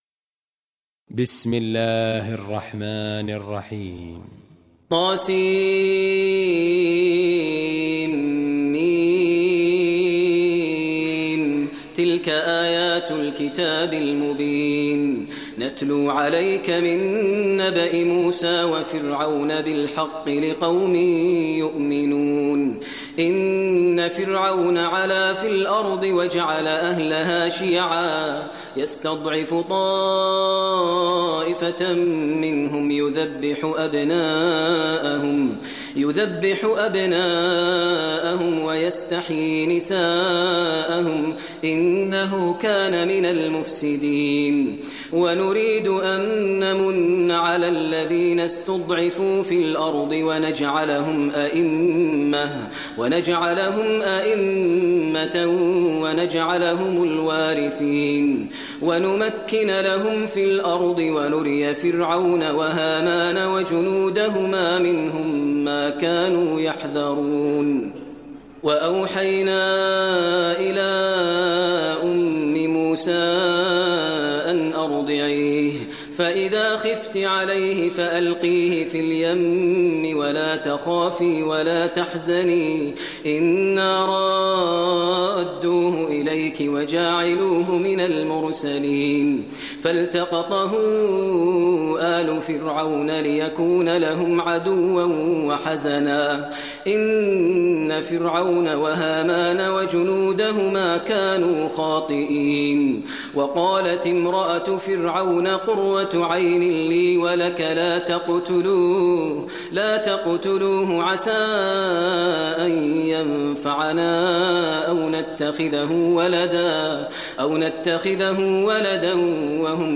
Surah Qasas Recitation by Maher al Mueaqly
Surah Qasas, listen online mp3 tilawat / recitation in Arabic recited by Imam e Kaaba Sheikh Maher al Mueaqly.